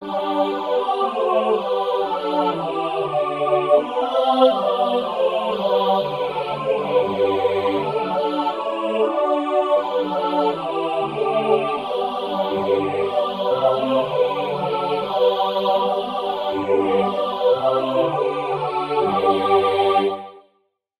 Composer: Martin Madan
Key: G Major